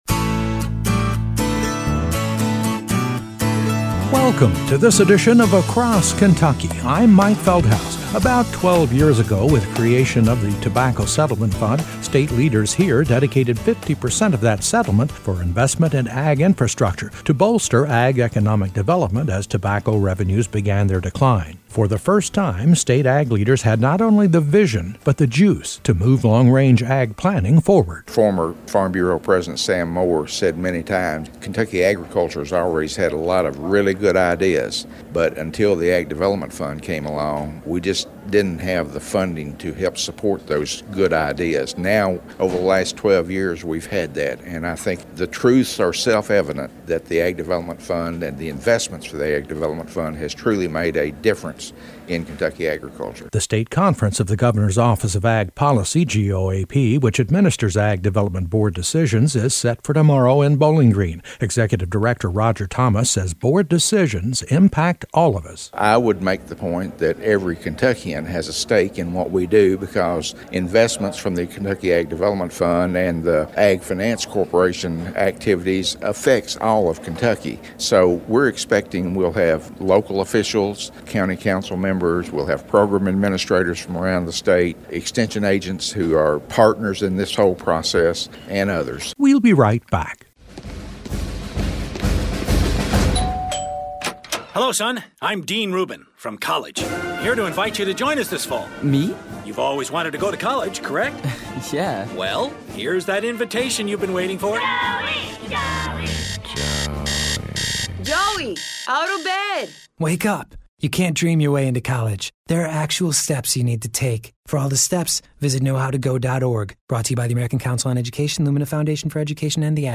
GOAP Executive Director Roger Thomas talks about the agencies work with the Ag Development Board and the tremendous progress in boosting ag infrastructure here.